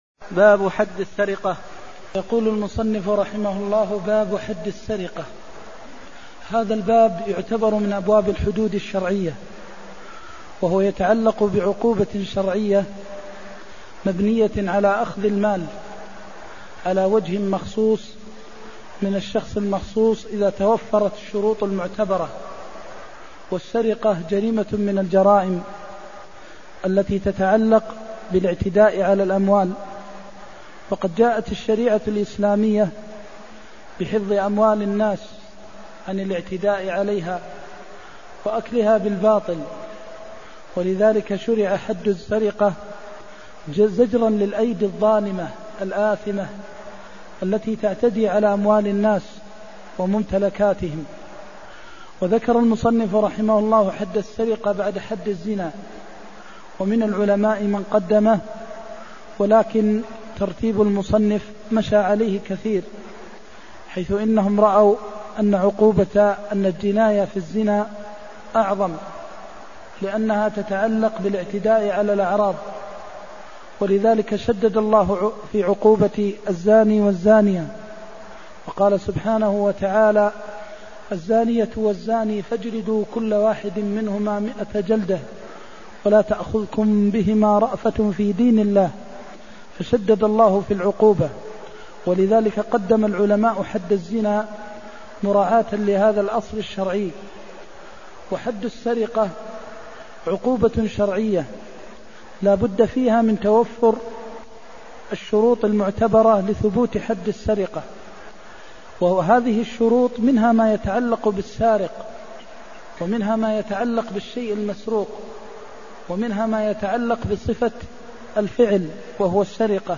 المكان: المسجد النبوي الشيخ: فضيلة الشيخ د. محمد بن محمد المختار فضيلة الشيخ د. محمد بن محمد المختار قطع في مجن ثمنه ثلاثة دراهم (334)